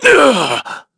Shakmeh-Vox_Damage_kr_05.wav